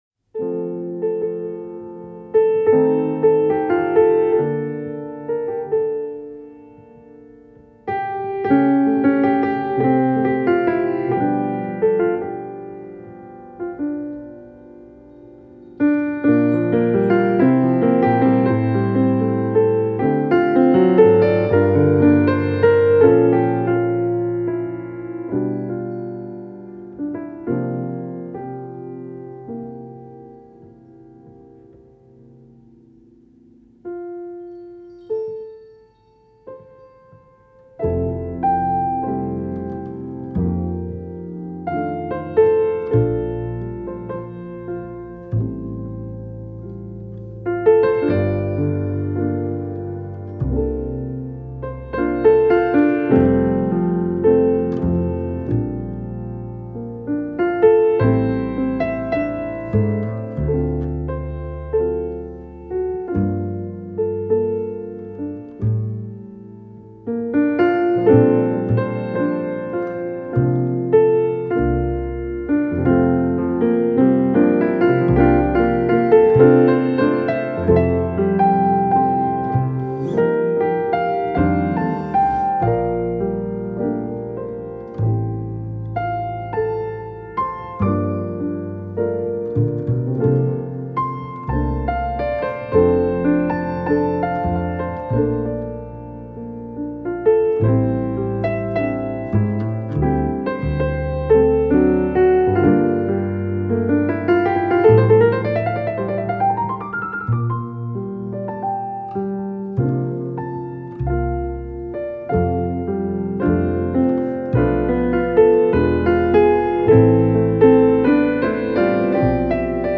double bass
piano
Il disco è un trionfo di gioia, ma anche di malinconia.